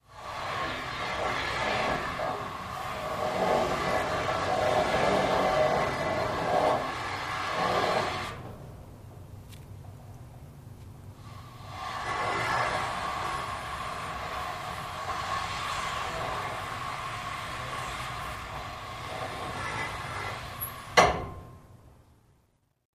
Stage Curtain: Pulley Sliding Open Close.